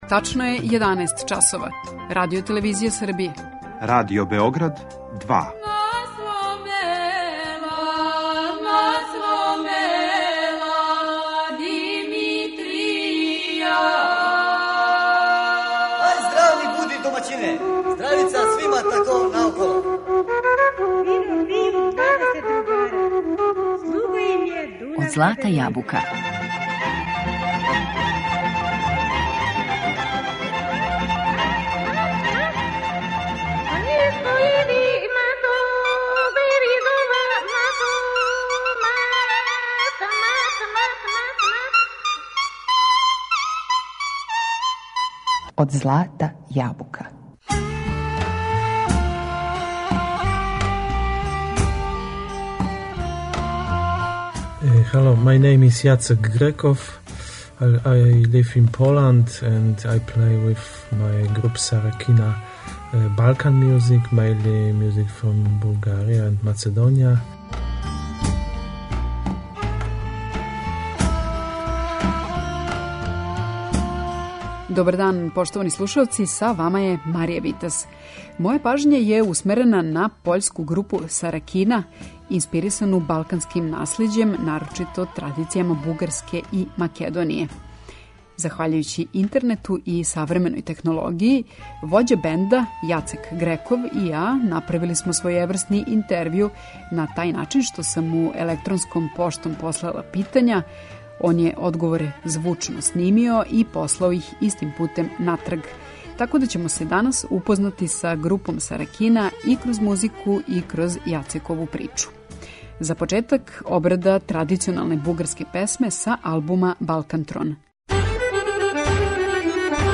Необичан бенд на пољској етно сцени, Саракина, инспирисан је балканским наслеђем, нарочито традицијама Бугарске и Македоније.